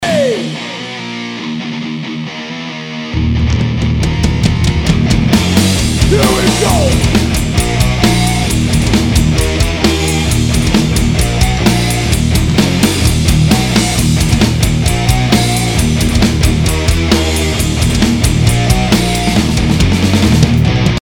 Rough mix here of a project I am working on.
Mesa Triple Rectifier used on all rhythm guitars.
Attachments Guitar Tone.mp3 Guitar Tone.mp3 820.7 KB · Views: 221 Guitar Tone W Drums n shit.mp3 Guitar Tone W Drums n shit.mp3 820.7 KB · Views: 203